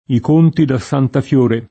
S#nta fL1ra] top. (Tosc.) — ant. Santa Fiore [S#nta fL1re] o Santafiore [id.]: E vedrai Santafior com’è oscura [e vvedr#i SantafL1r kom $ oSk2ra] (Dante); i conti da Santa Fiore [